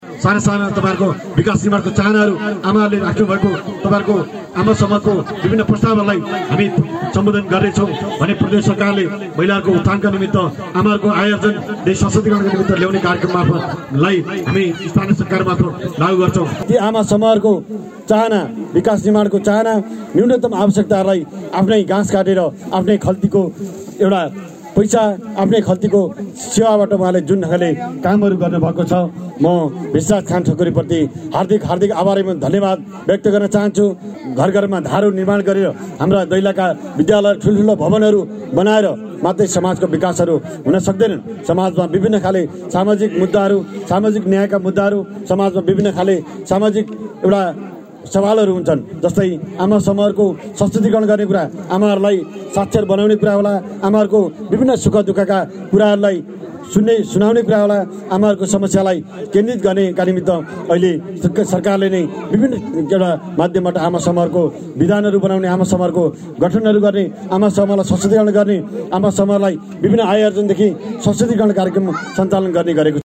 कार्यक्रमलाई सम्बोधन गर्दै नीलकण्ठ नगरपालिकाका उप–प्रमुख दिपक विश्वकर्माले समूदाय केन्द्रित बिकासको अवधारणालाई आत्मसाथ गरि आगामी आर्थिक बर्षको नीती तथा कार्यक्रममा समावेश गर्ने बताउनुभयो ।